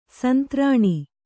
♪ santrāṇa